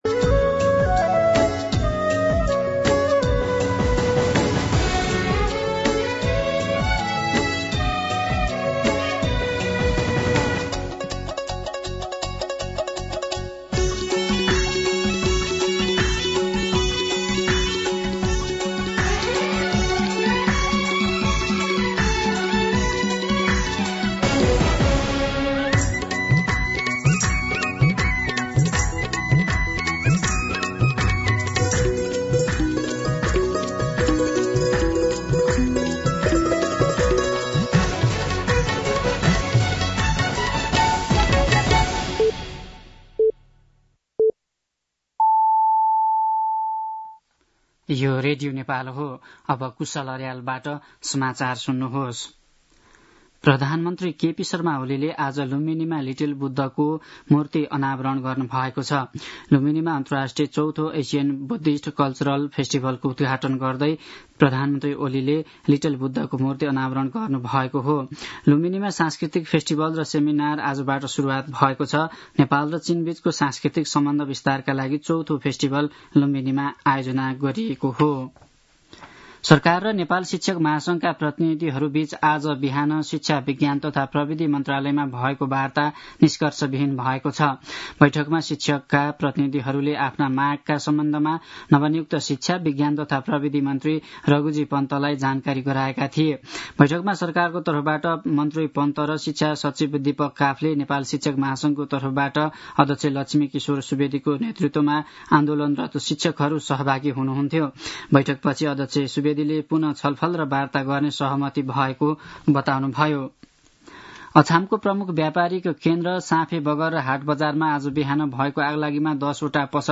मध्यान्ह १२ बजेको नेपाली समाचार : १३ वैशाख , २०८२